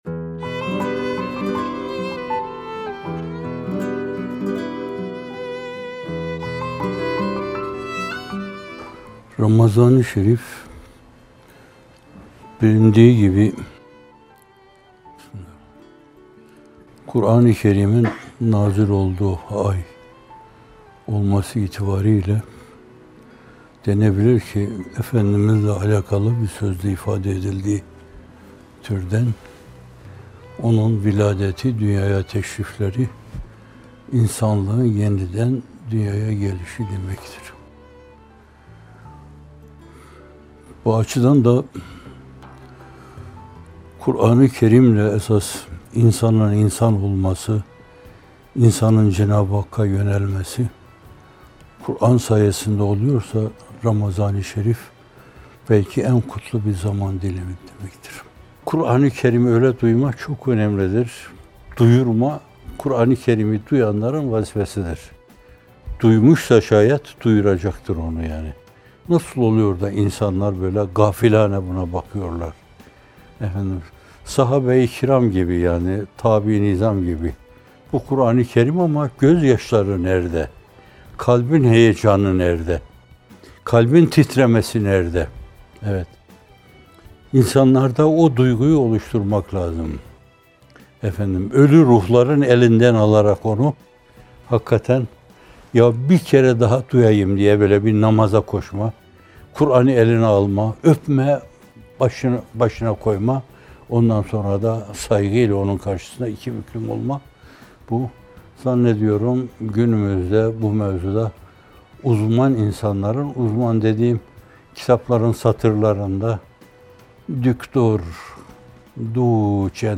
Bir Nefes (69) – Ramazan-ı Şerif: En Kutlu Zaman Dilimi - Fethullah Gülen Hocaefendi'nin Sohbetleri